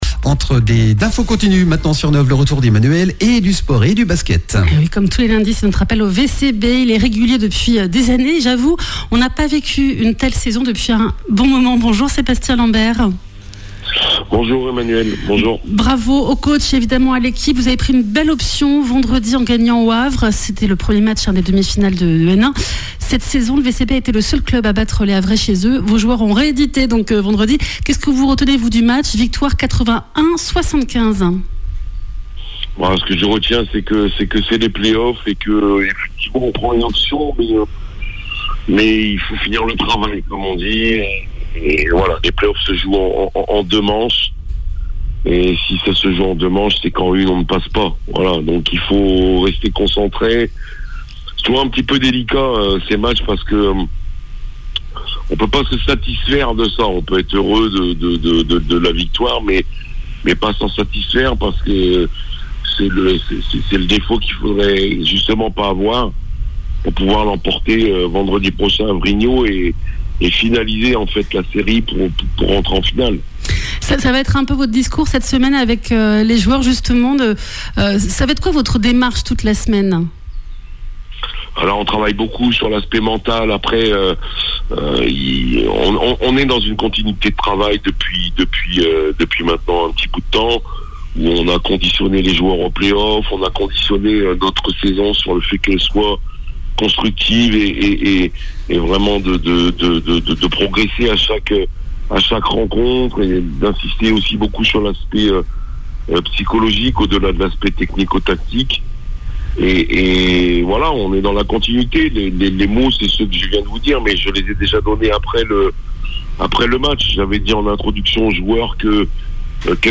Notre appel au VCB du lundi matin. Challans a pris une belle option vendredi, en gagnant au Havre (75-81), premier match des demi-finales de N1 !